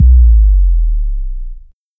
Kick OS 20.wav